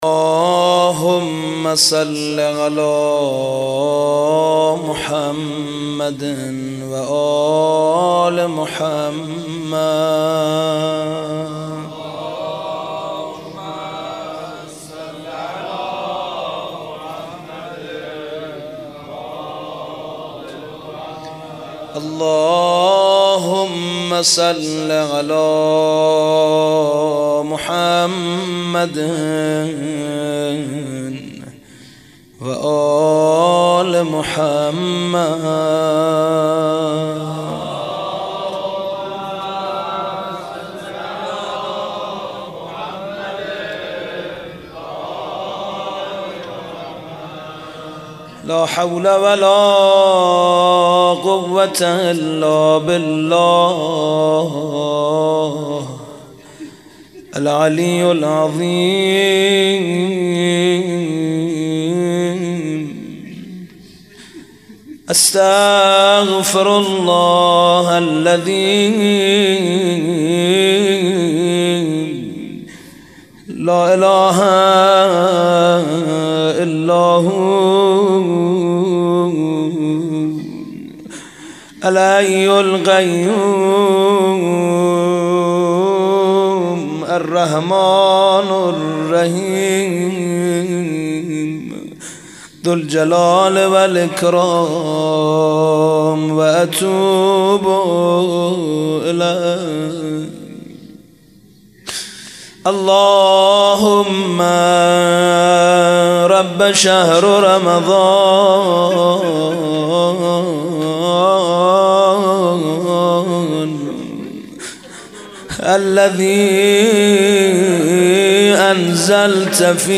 روضه و مناجات
اگر برآید چو مرغی ز پیکر خسته ام پر روضه محمود کریمی